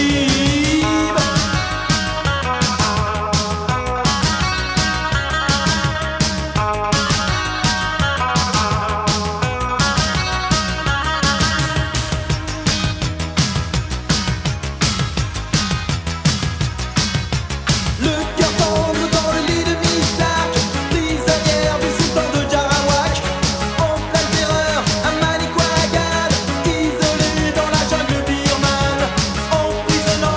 Rock
Chanson française